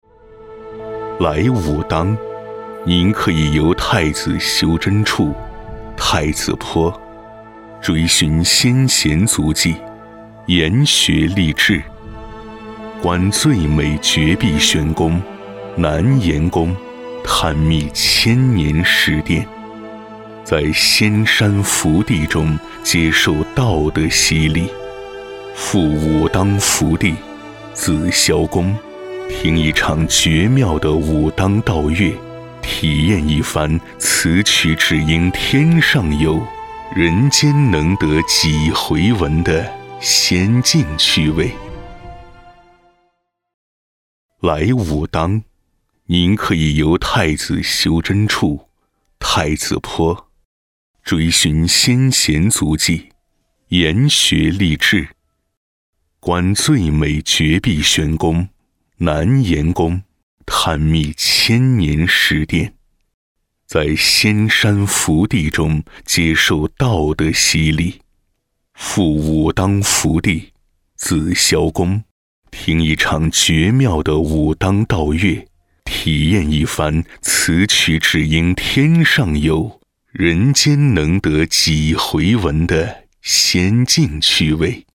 淘声配音网，专题，宣传片配音，专业网络配音平台 - 淘声配音网配音师男国语118号 国语配音 方言配音
签约技能： 广告 专题 走心旁白 角色
配音风格： 国语配音 方言配音
旁白 来武当